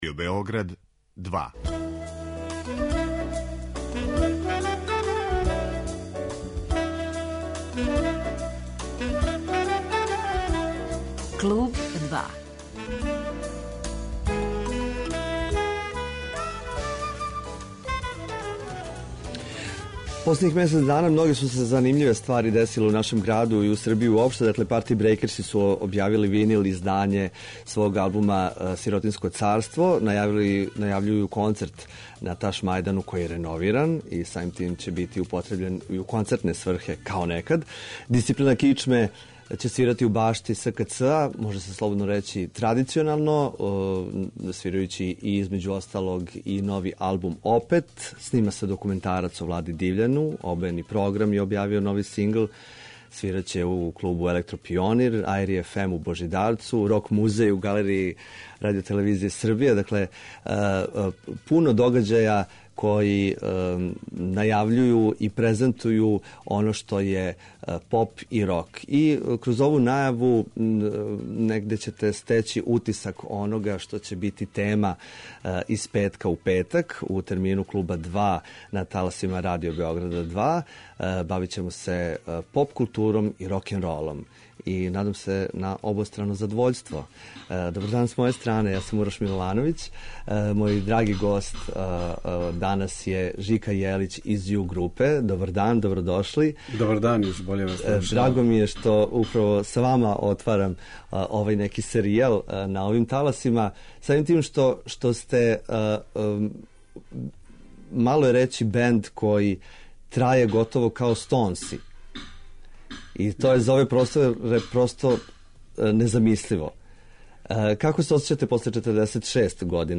Гост емисије биће Жика Јелић